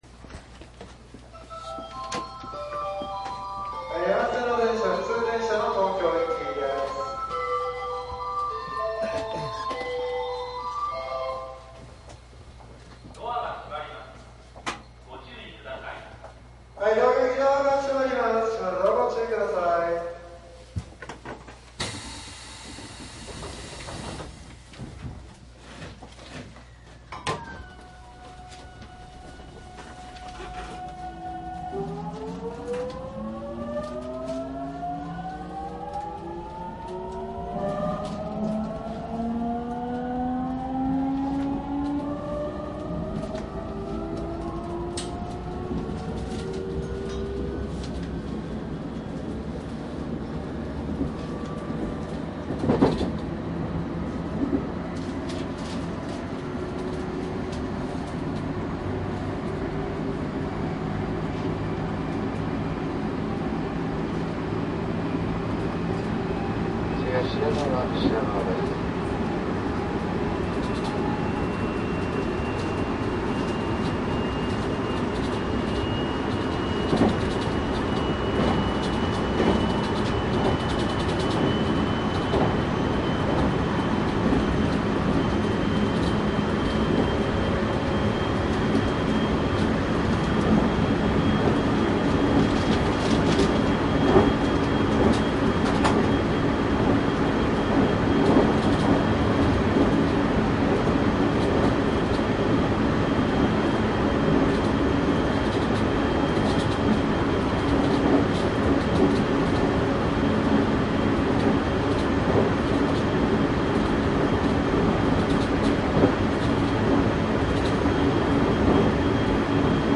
JR東海道線 373系走行音CD♪
内容はJR東海道線 上り 373系走行音の収録です。
■【普通】熱海→東京 クモハ373- ９
サンプル音声 クモハ373- ９ .mp3
マスター音源はデジタル44.1kHz16ビット（マイクＥＣＭ959）で、これを編集ソフトでＣＤに焼いたものです。